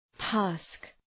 Προφορά
{tæsk}